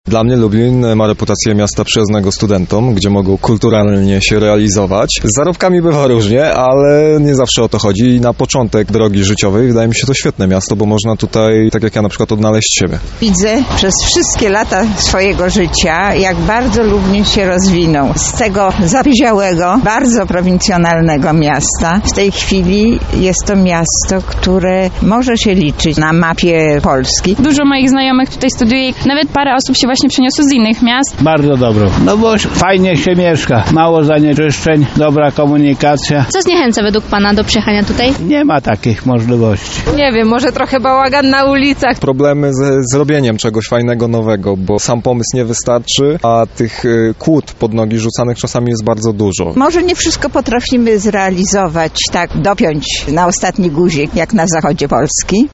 sonda